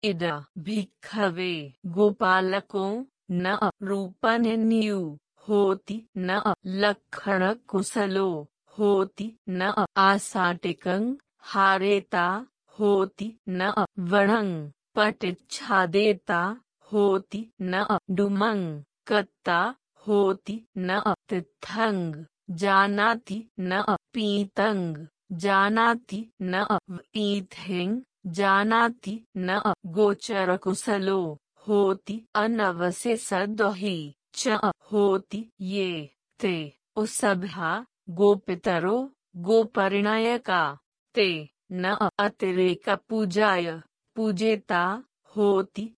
We’re currently addressing Voice Pali pronunciation fixes for version 2.3. Our goal is not to have perfect Pali pronunciation, which is a bit out of reach for current AI.
The proposed change affects all Pali long ī. Here is the passage after curing Aditi’s hiccups. Note that long ī occurs in pītaṃ as well as vīthiṃ and anavasesadohī: